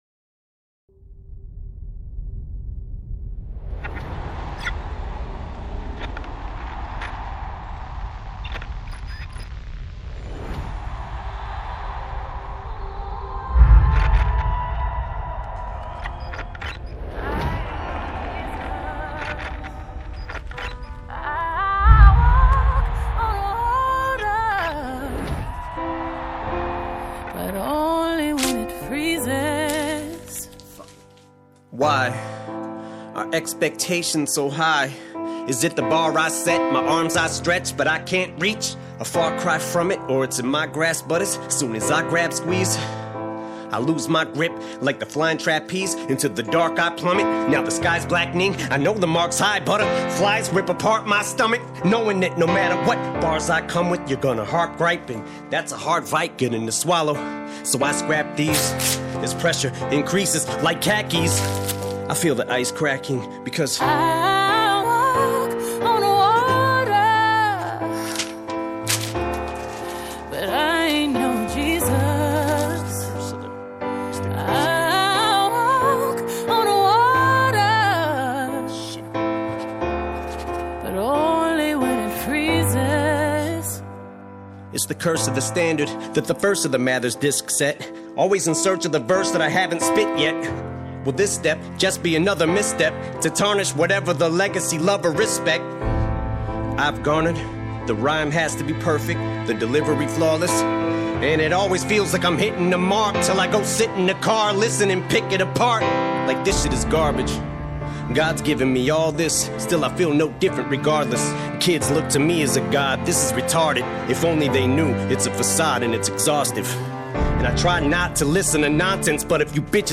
Hip Hop, Rap